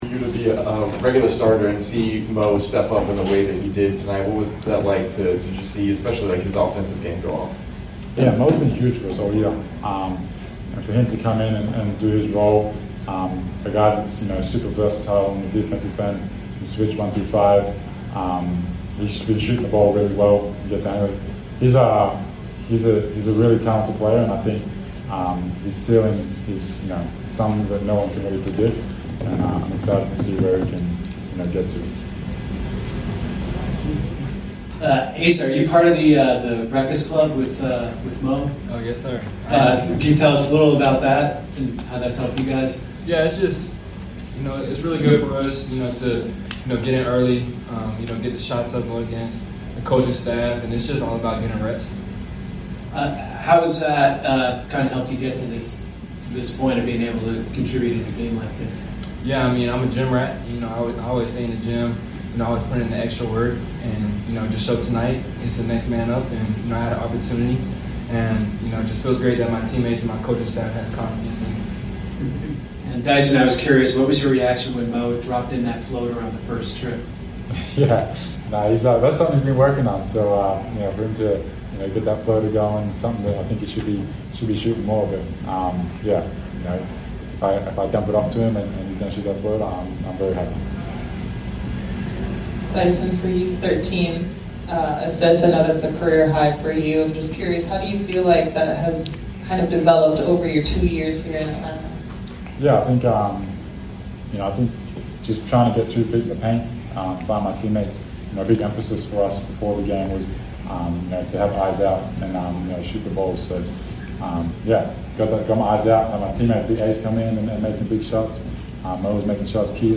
Atlanta Hawks Forward Asa Newell and Guard Dyson Daniels Postgame Interview after defeating the Los Angeles Lakers at State Farm Arena.